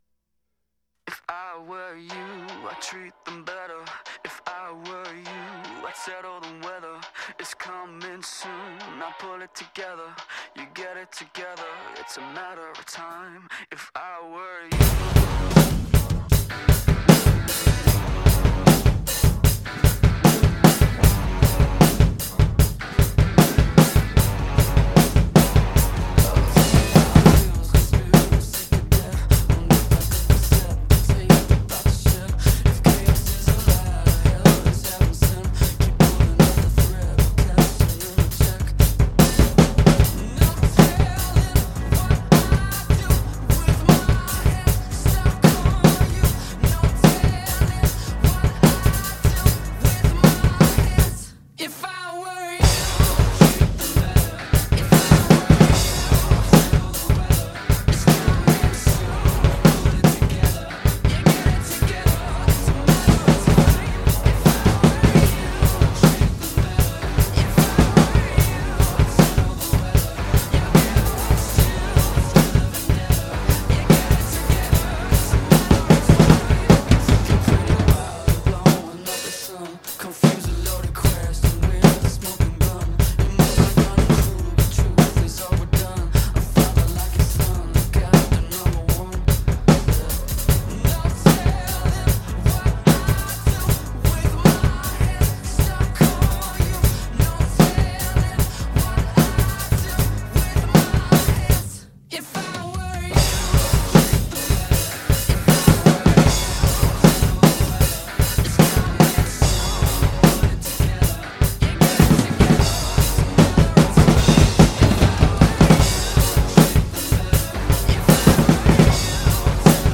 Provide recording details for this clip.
Short clip of play along taken during my practice yesterday.